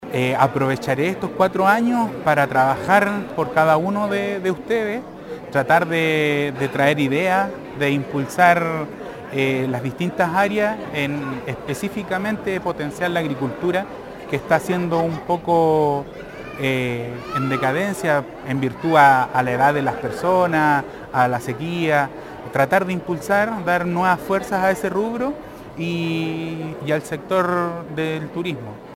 Mientras que el concejal Solano Portilla comentó que trabajará por fortalecer la agricultura entre los vecinos.